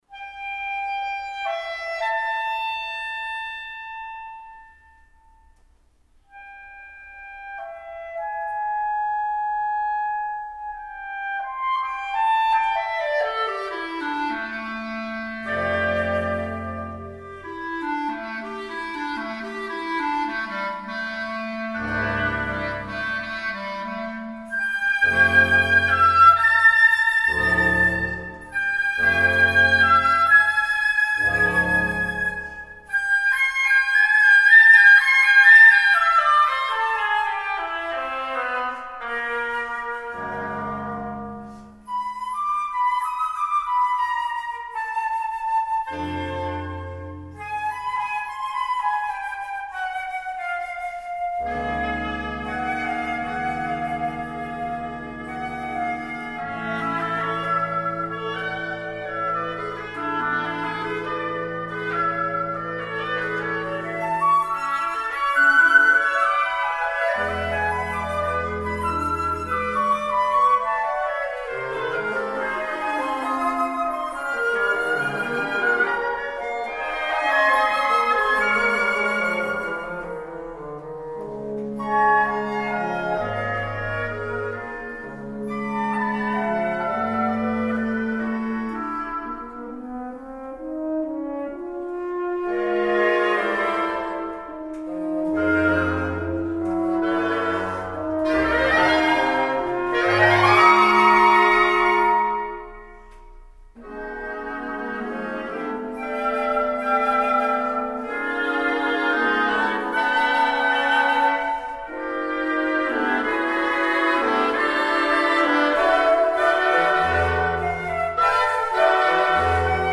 música brasileira para quinteto de sopros